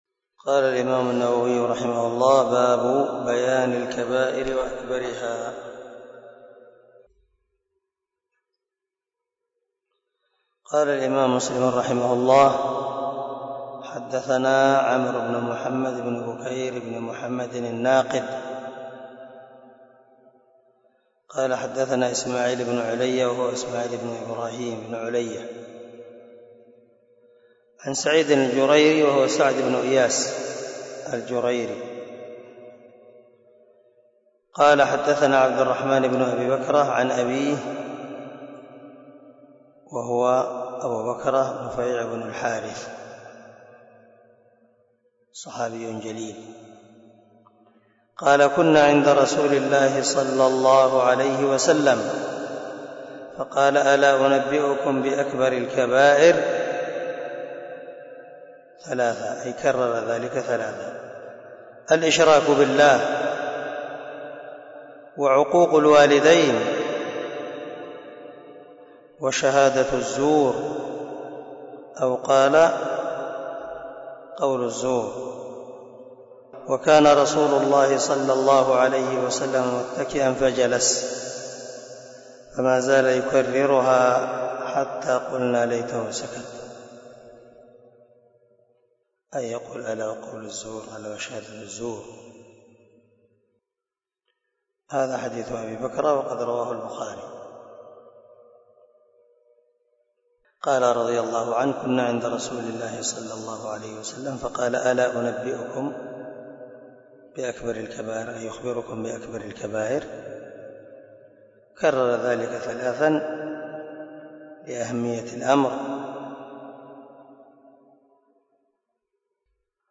سلسلة_الدروس_العلمية
دار الحديث- المَحاوِلة- الصبيحة